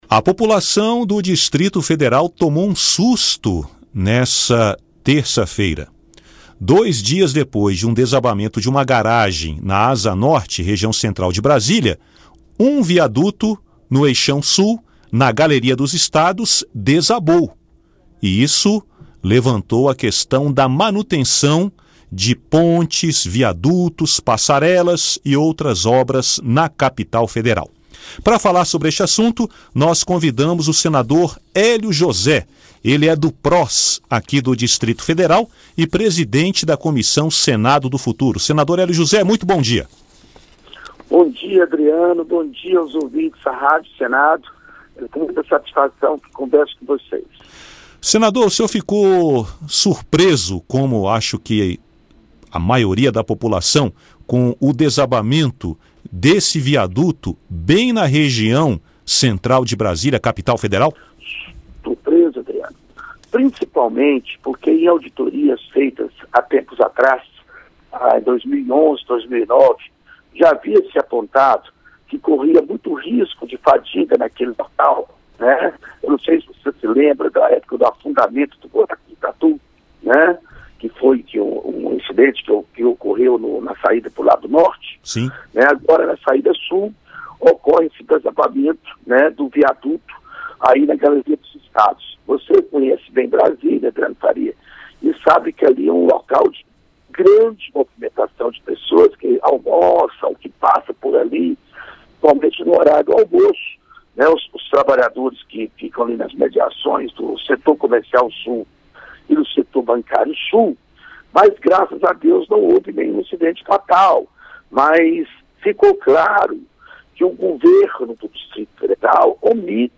O desabamento de parte de um viaduto no Eixão Sul, em Brasília, nessa terça-feira (6), motivou o senador Hélio José (Pros-DF) a apresentar um requerimento de audiência pública para debater a manutenção desse tipo de estrutura. Presidente da Comissão Senado do Futuro (CSF), Hélio José anunciou, em entrevista à Rádio Senado, que pretende apresentar o requerimento na reunião desta quarta-feira (7).